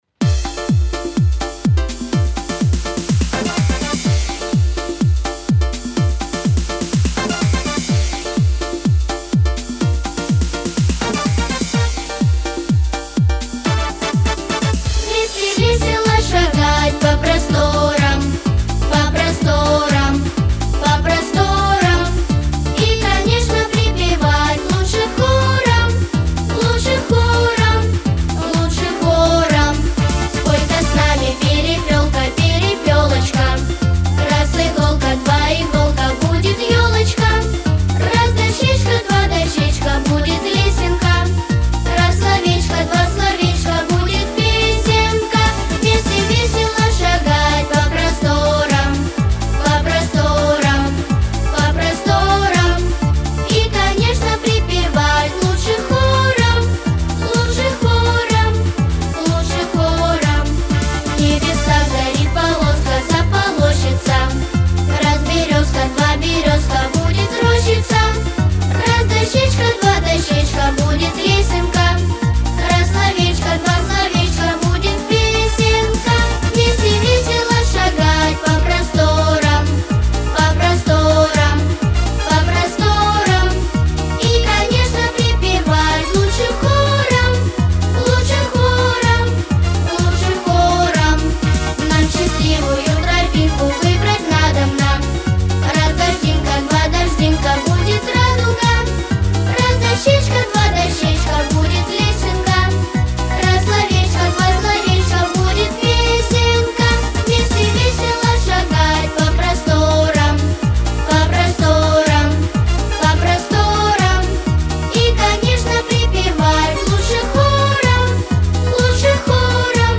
Вокальный ансамбль
• Категория: Детские песни